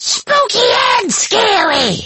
Kr4_fallen_ones_bone_flingers_taunt_1.mp3